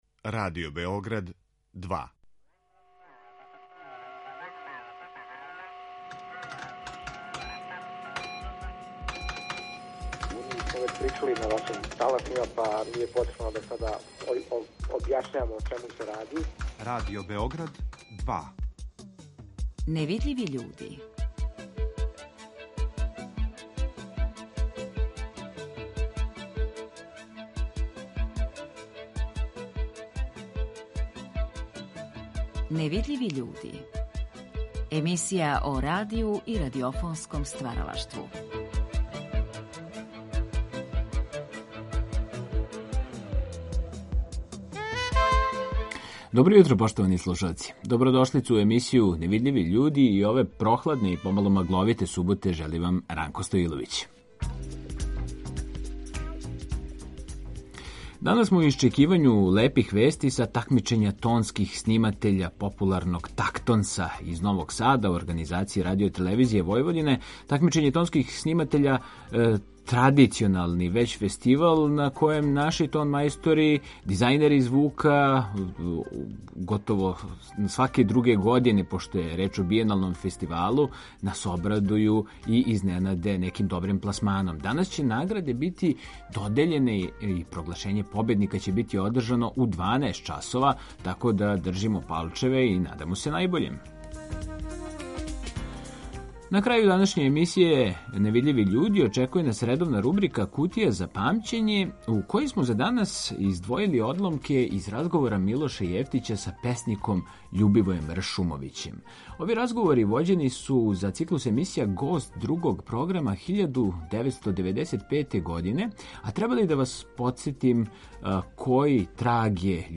Емисија о радију и радиофонском стваралаштву